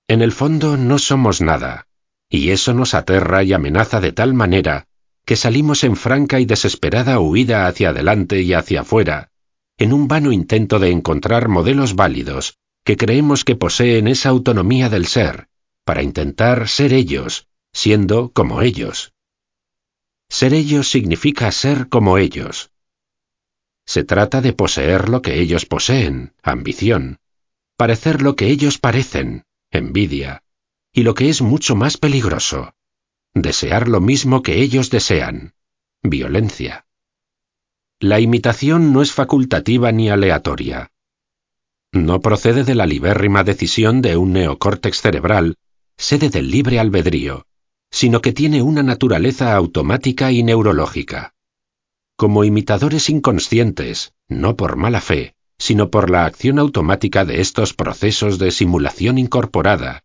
audiolibro Libertad Zero La liberacion interior para ser tu mejor version Inaki Pinuel